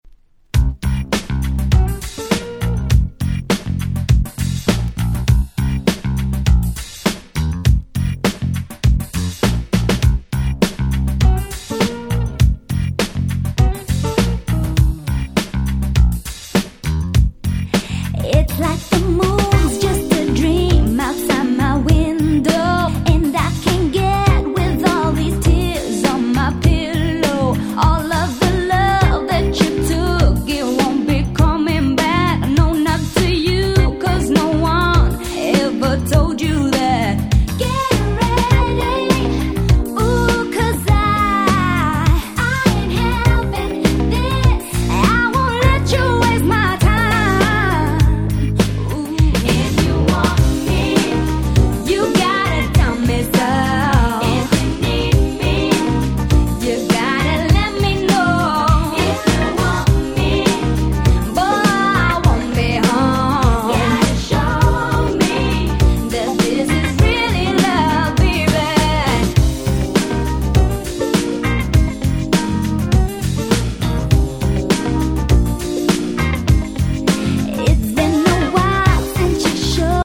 98' Nice UK R&B !!
キャッチー系